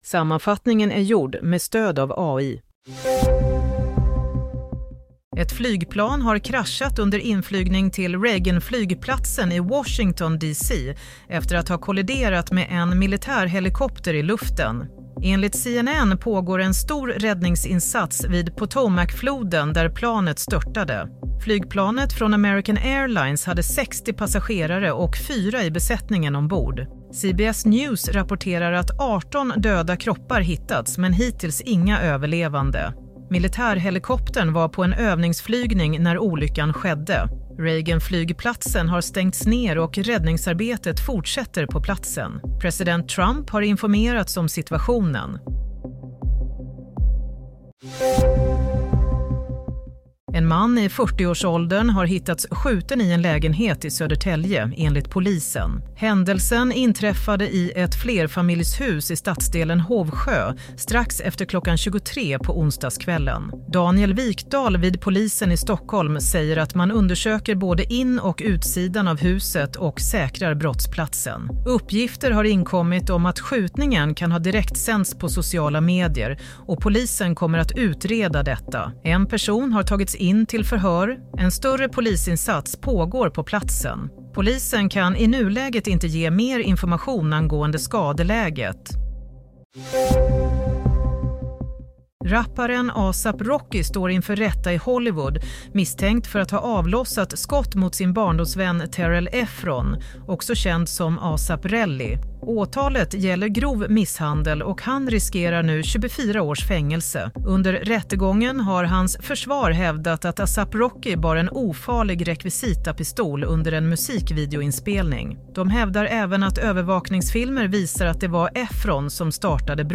Nyhetssammanfattning - 30 januari 07:00